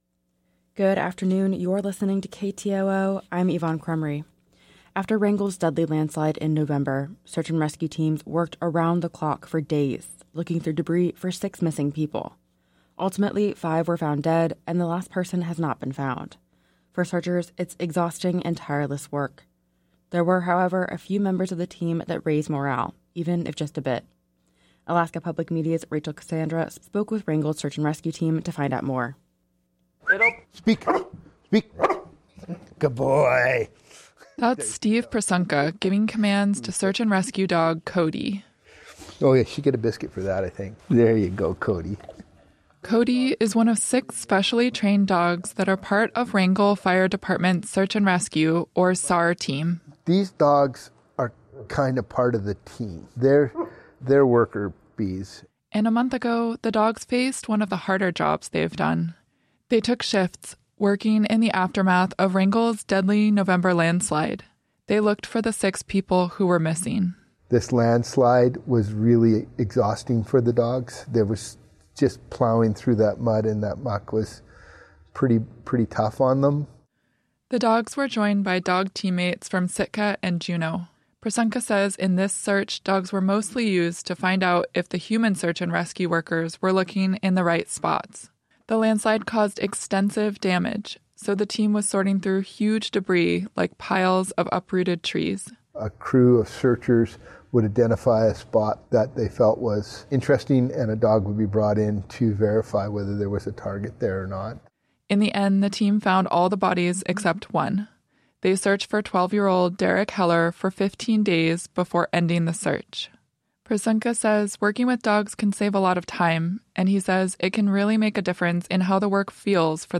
Newscast – Tuesday. Jan. 9 2024